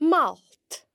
For now, the silent fh can be heard in m’ fhalt (my hair):